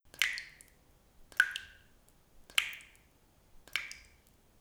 water-drops-1.wav